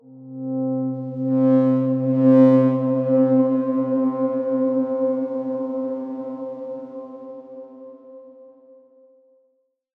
X_Darkswarm-C3-ff.wav